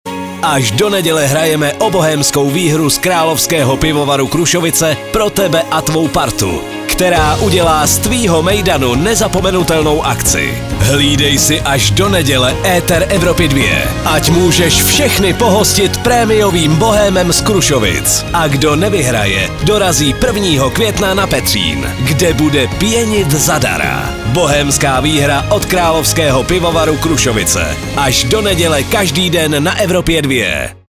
spot-krusovice-2023.mp3